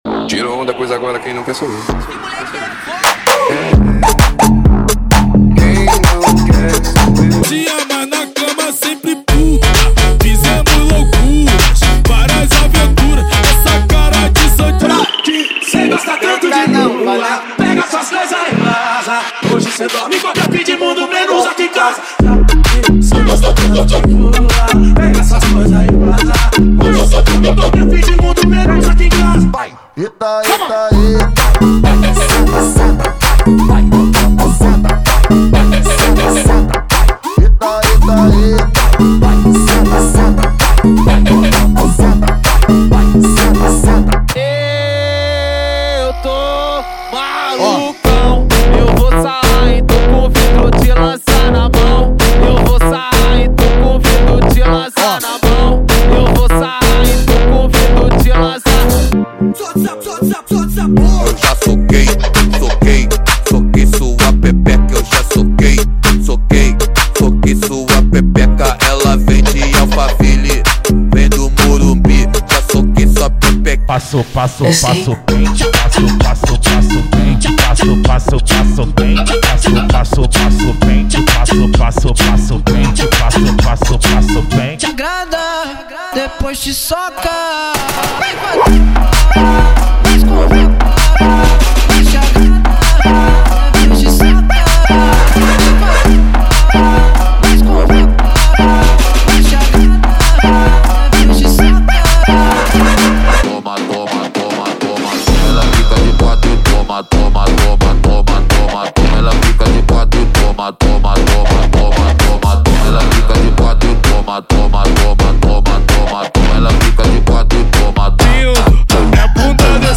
• Eletro Funk = 50 Músicas
• Sem Vinhetas
• Em Alta Qualidade